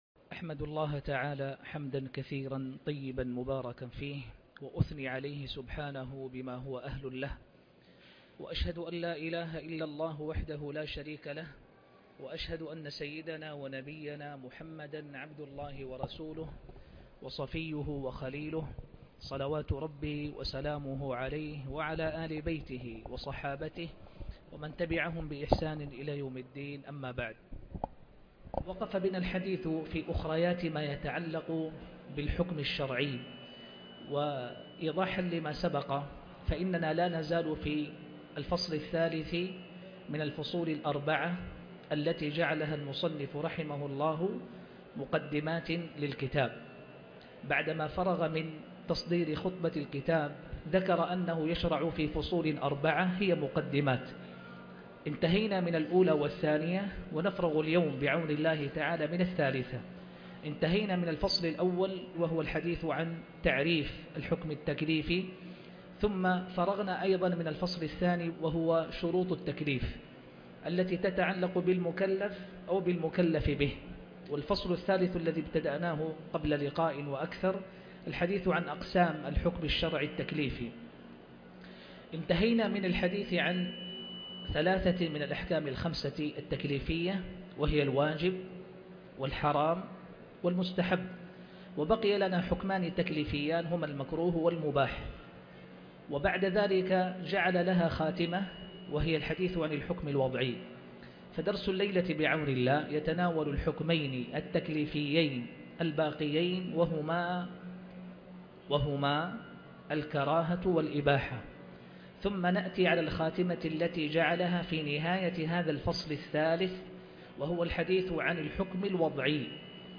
18|شرح مختصر الروضة(معاقد الأصول)